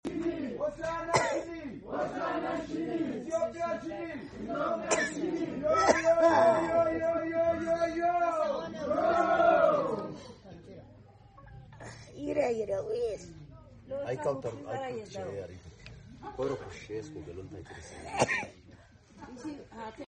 Elder with traditional Huka, Dorze